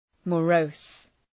{mə’rəʋs}
morose.mp3